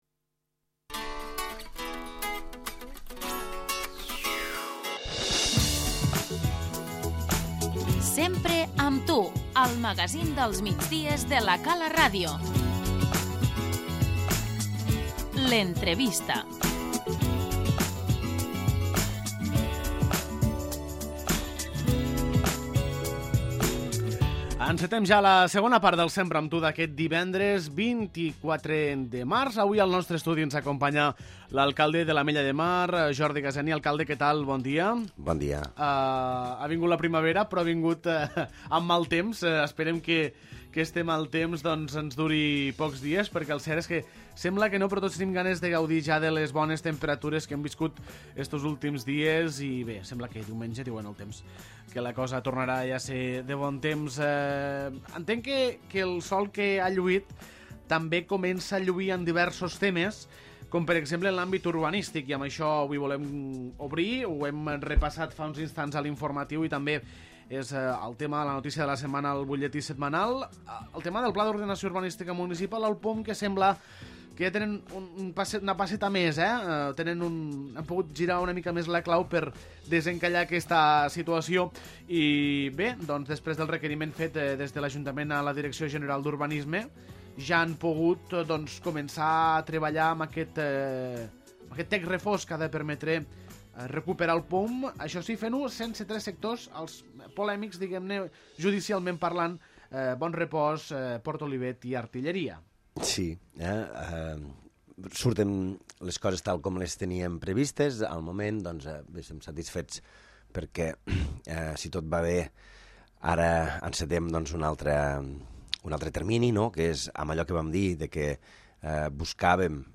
L'entrevista - Jordi Gaseni, alcalde de l'Ametlla de Mar
L'alcalde de l'Ajuntament de l'Ametlla de Mar, Jordi Gaseni, ens ha visitat aquest divendres per parlar sobre temes urbanístics, econòmics, de millores i actuacions, i de turisme.